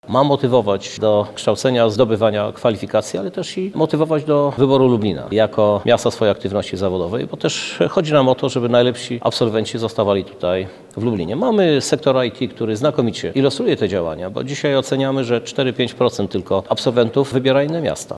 Jak powiedział prezydent Lublina Krzysztof Żuk, jest to jedna z zachęt do tego, aby młodzi ludzie, po ukończeniu studiów pozostawali w Lublinie.